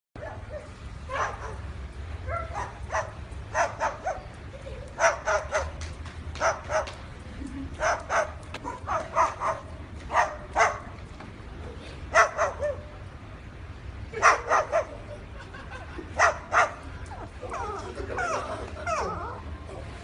Papagaio latindo
papagaio-latindo.mp3